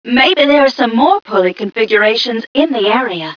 mission_voice_m1ca022.wav